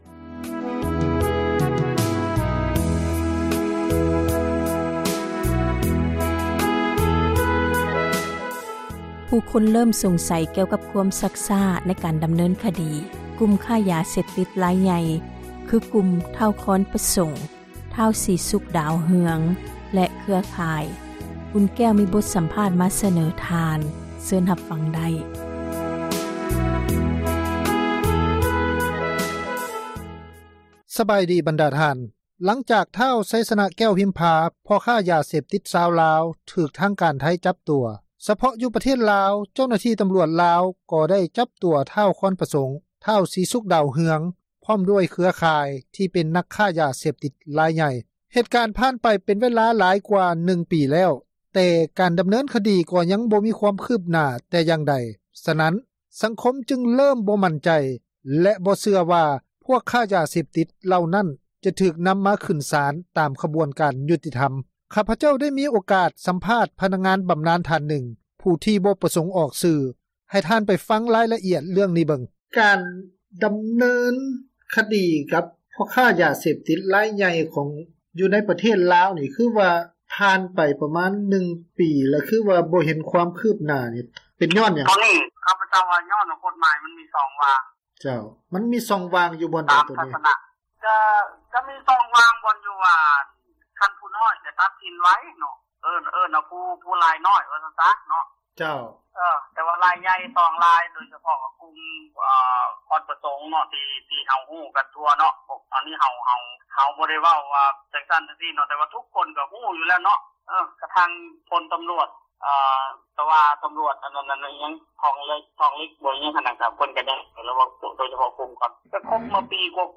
ຂ້າພະເຈົ້າໄດ້ມີໂອກາດ ສຳພາດ ພະນັກງານ ບຳນານທ່ານນຶ່ງ ຜູ້ທີ່ບໍ່ປະສົງ ອອກຊື່, ໃຫ້ ທ່ານ ໄປຟັງຣາຍລະອຽດ ໃນເລື້ອງນີ້ເບິ່ງ.